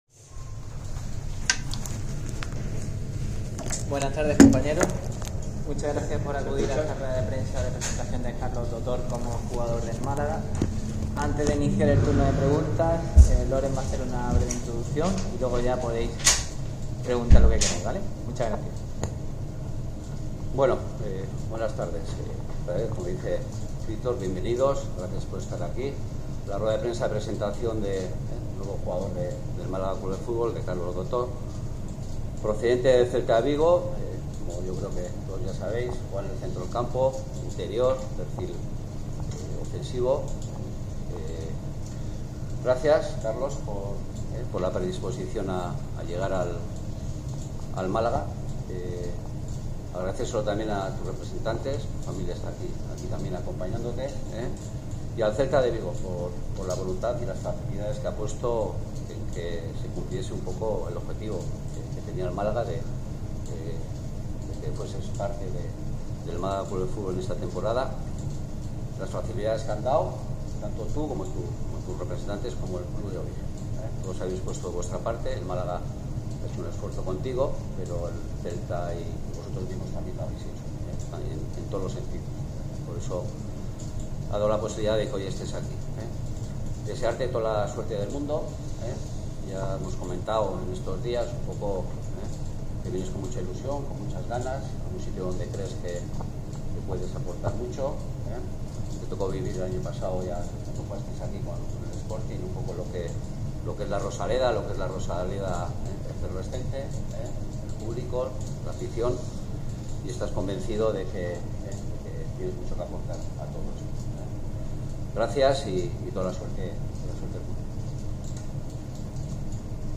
ha hablado por primera vez como jugador blanquiazul en la rueda de prensa de su presentación.